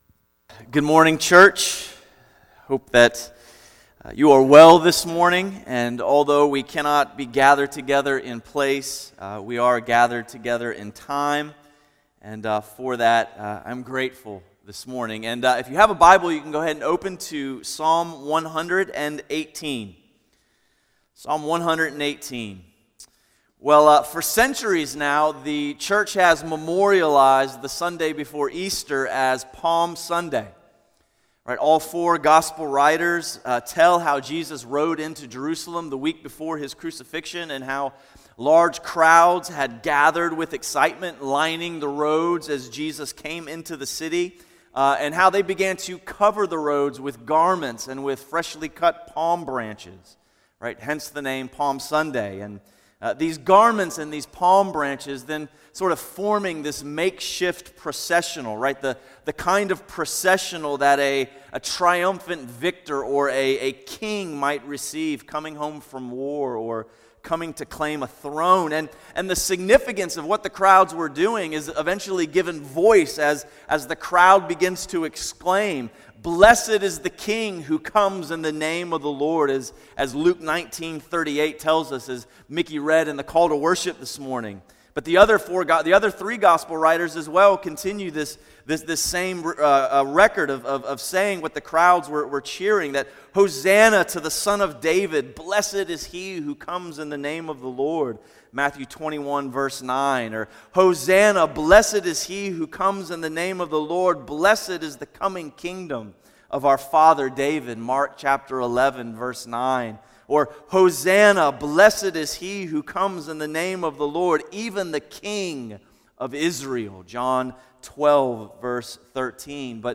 A message from the series "New Life in Jesus."